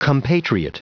Prononciation du mot compatriot en anglais (fichier audio)
Prononciation du mot : compatriot